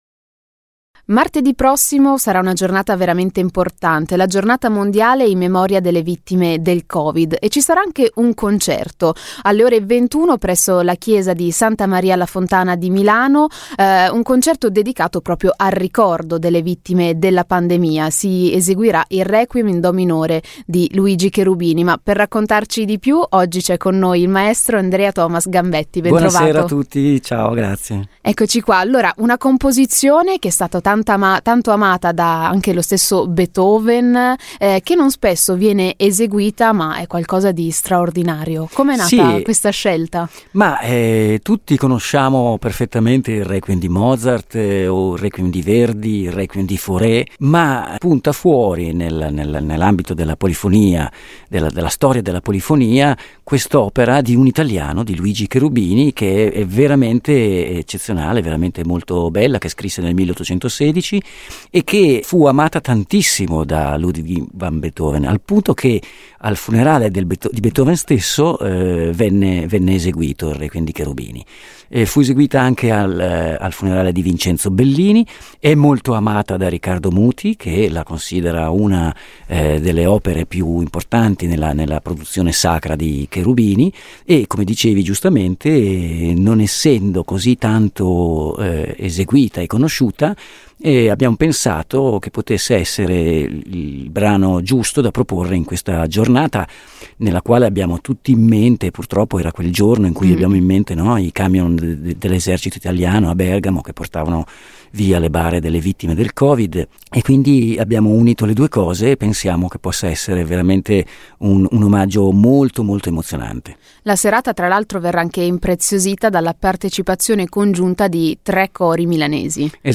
Radio Marconi intervista a Casa Marconi - Corale Polifonica Città Studi